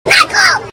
explode1.ogg